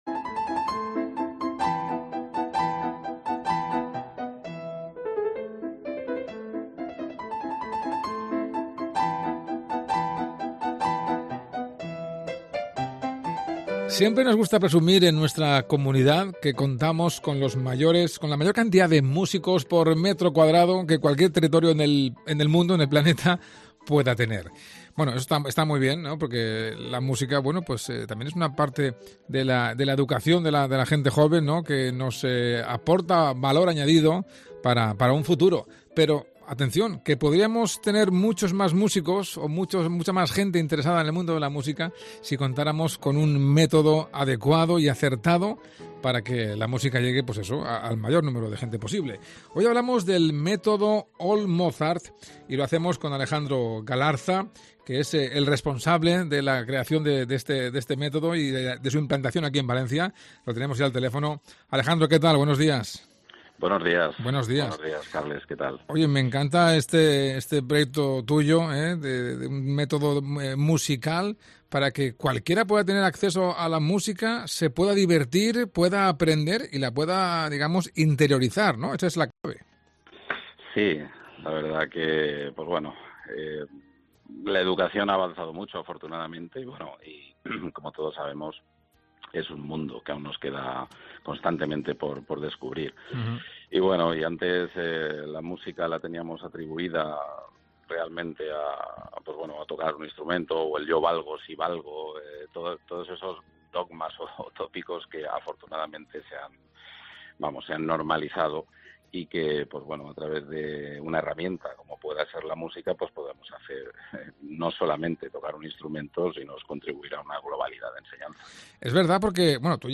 Mediodía COPE MÁS Valencia | Método All Mozart. Entrevista